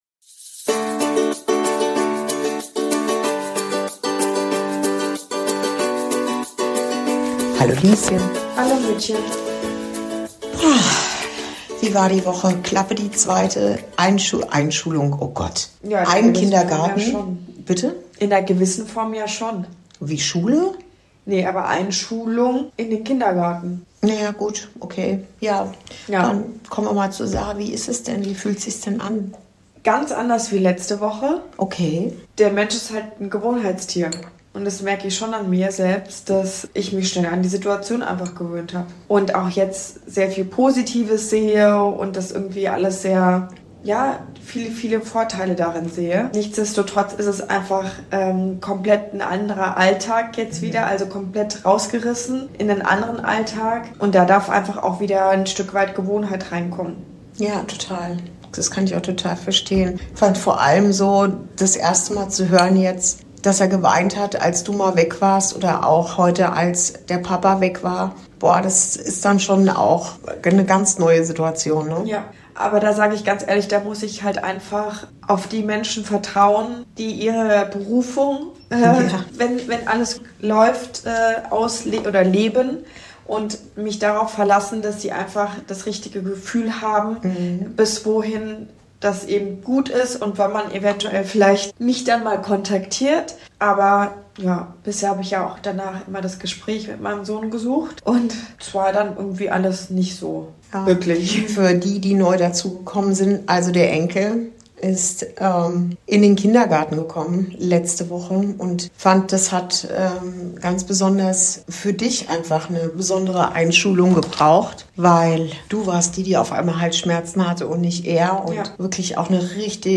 Folge 17: Von kleinen Umbrüchen & großen Vertrauen ~ Inside Out - Ein Gespräch zwischen Mutter und Tochter Podcast